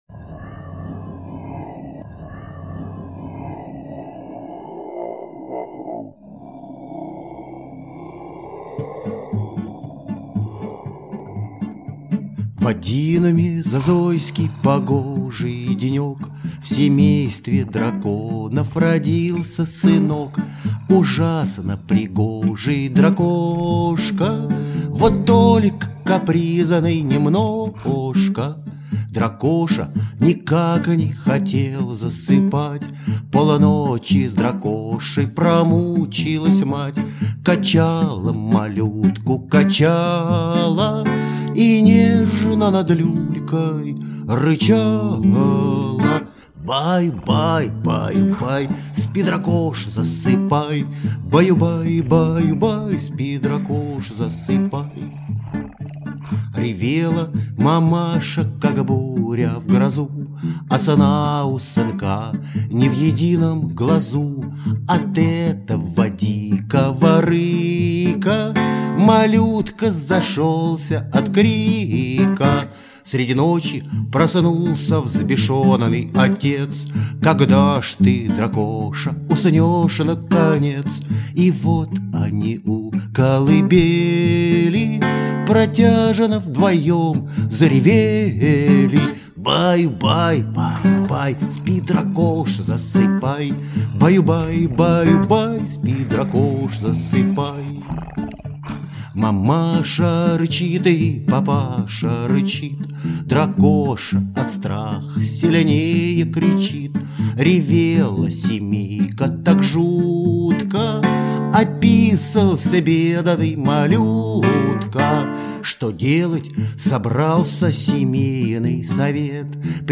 • Качество: Хорошее
• Категория: Детские песни
гитара